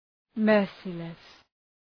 Προφορά
{‘mɜ:rsılıs}